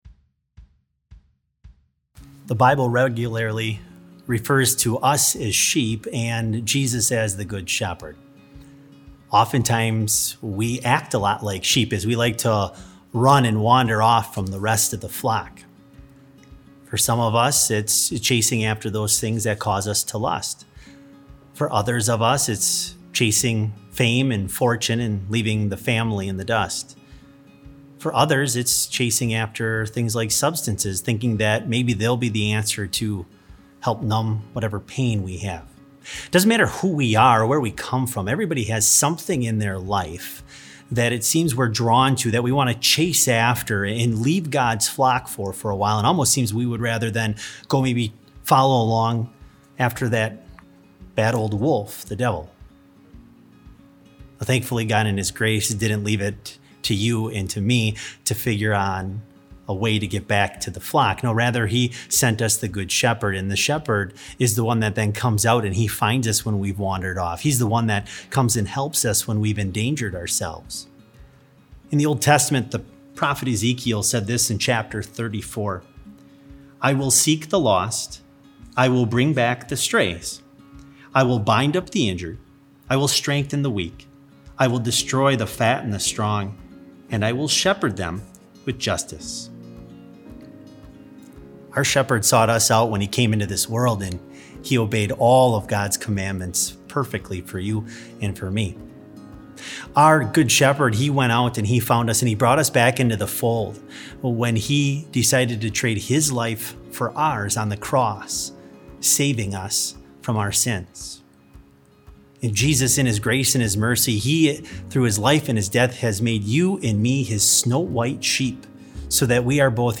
Complete service audio for BLC Devotion - April 27, 2020